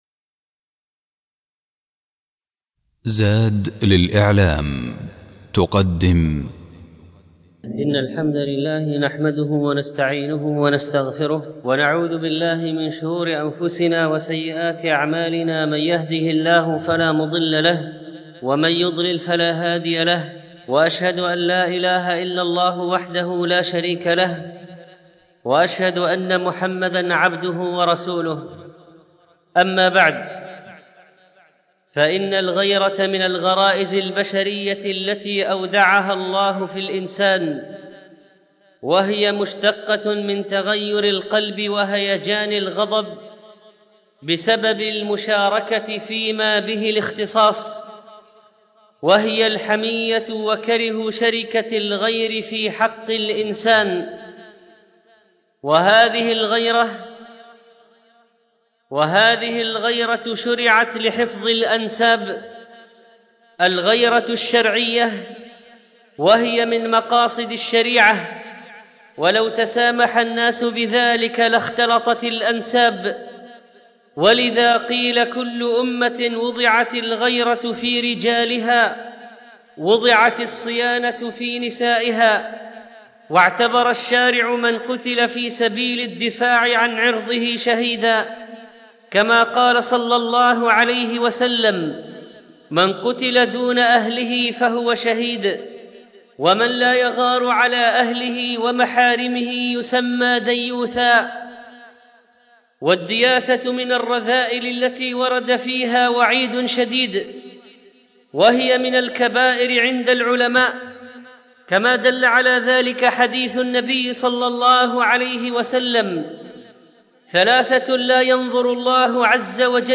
الخطبة الأولى